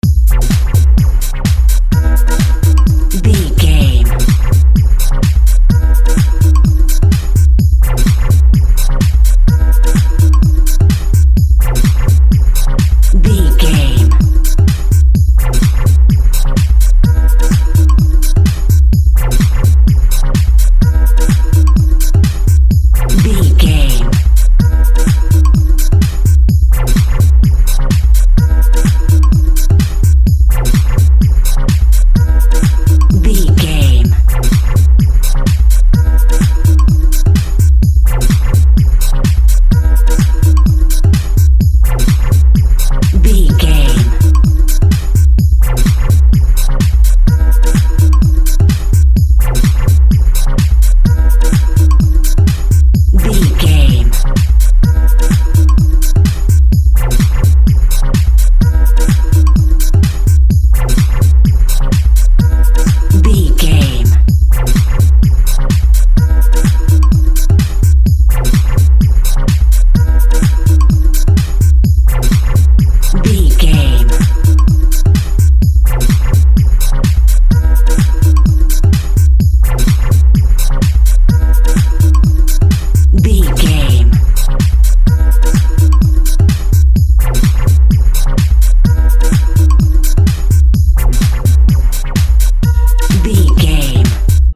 Epic / Action
Fast paced
Aeolian/Minor
hard
intense
energetic
driving
repetitive
dark
synthesiser
drum machine
progressive house
synth lead
synth bass